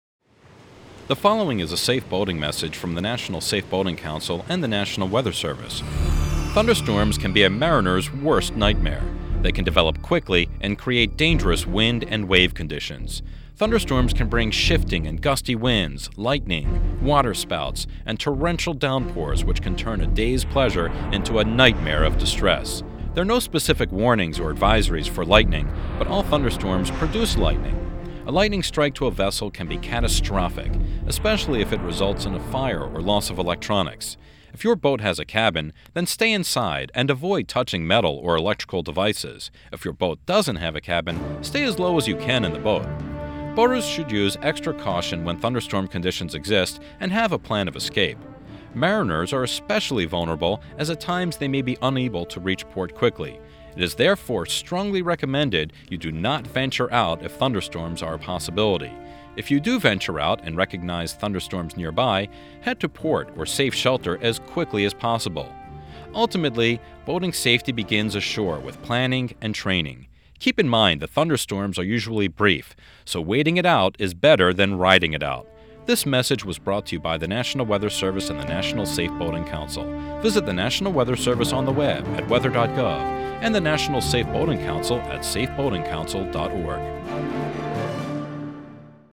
The following is a public service announcement for Safe Boating Week: Thursday